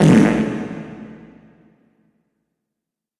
Fart With Reverb